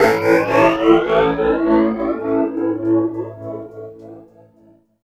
18 GUIT 4 -L.wav